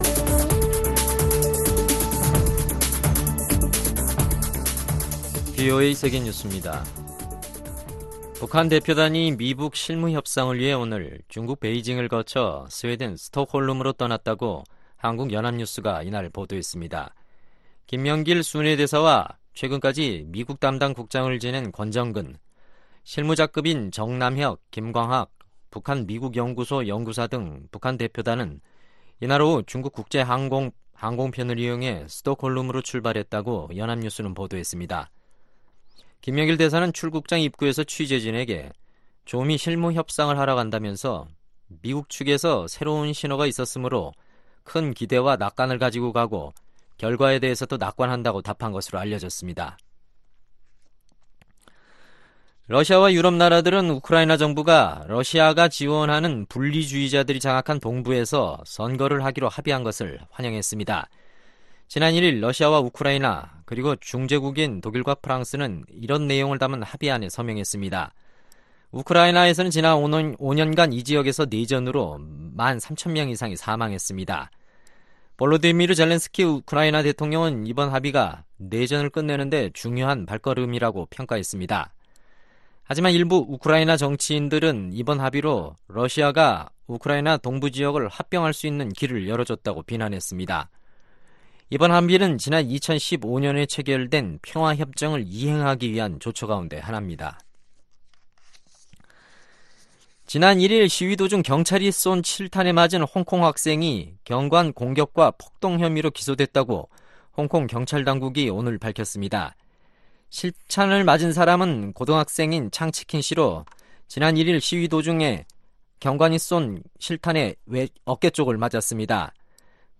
VOA 한국어 간판 뉴스 프로그램 '뉴스 투데이', 2019년 10월 3일 3부 방송입니다. 미 상원 코리 가드너 동아태 소위원장이 북한의 미사일 발사에 대해 트럼프 행정부에 최대 압박으로 돌아갈 것을 촉구했습니다. 미국의 전문가들은 북한의 이번 미사일 발사가 미-북 실무협상에서 미국의 입지를 더욱 어렵게 만들것으로 내다 봤습니다.